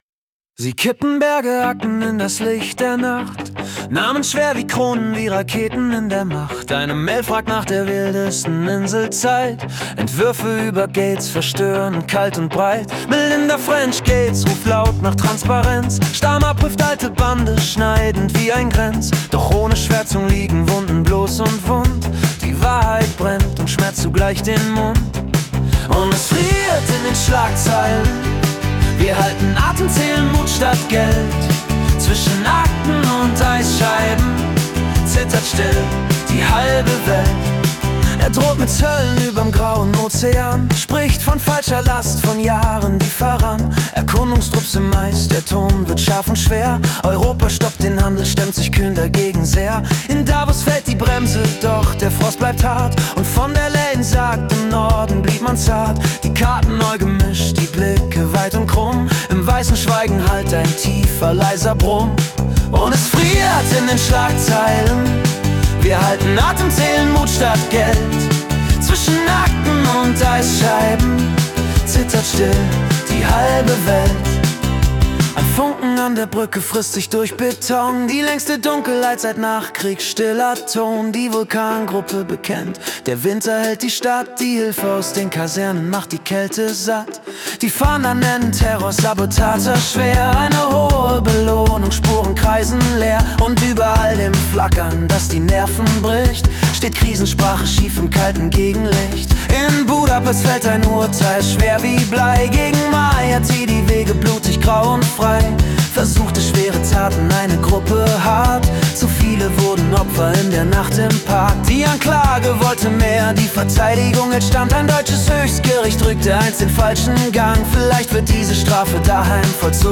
Die Nachrichten vom 5. Februar 2026 als Singer-Songwriter-Song interpretiert.